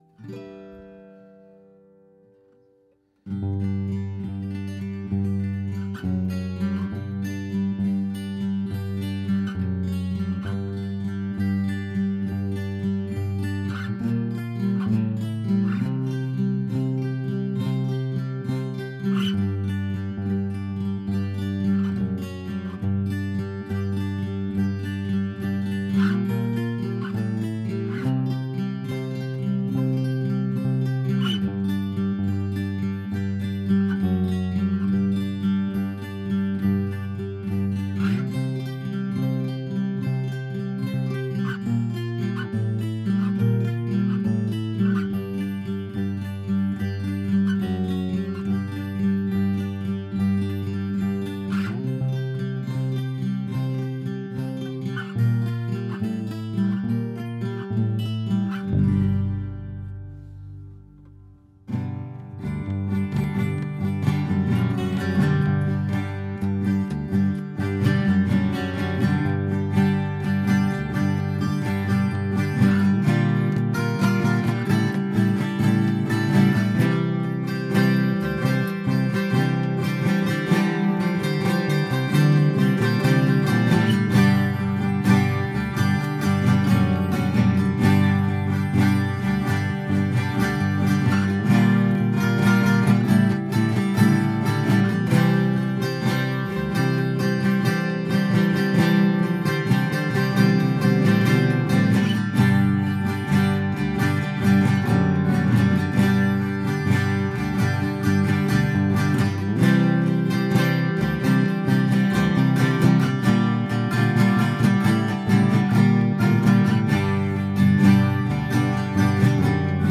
guitar is a Washburn EA20SDL miced with a Rode NT into a Mackie 1402 VLZ pro into a Delta 1010 slaved to a Lucid GENx6 - 96 superclock into Reaper Bass is an SX p bass copy and is direct into the Mackie and etc.etc......just compression on the bass...that's all I could figure out.....
it's just a G Major chord moving up and down the fretboard....